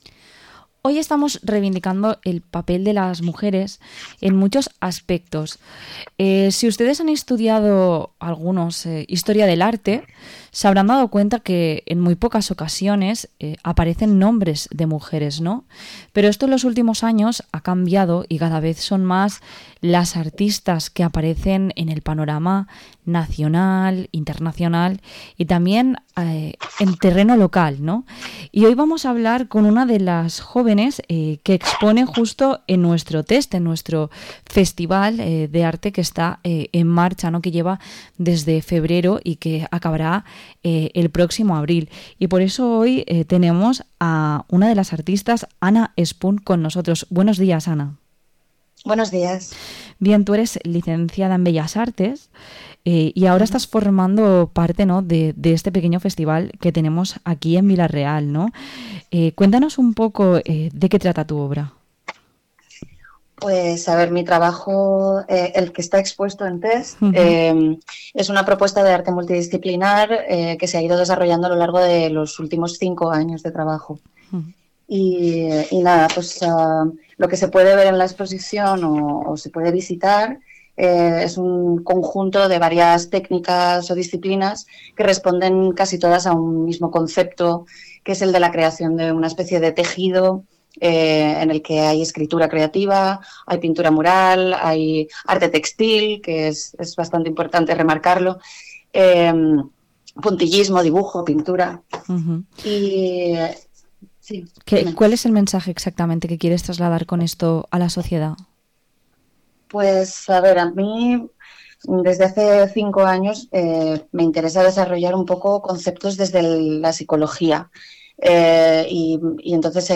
Entrevista a la artista